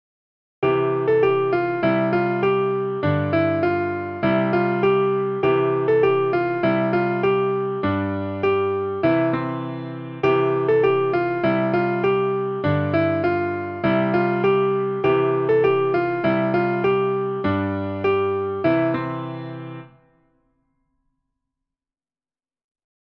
a Nursery Song
for piano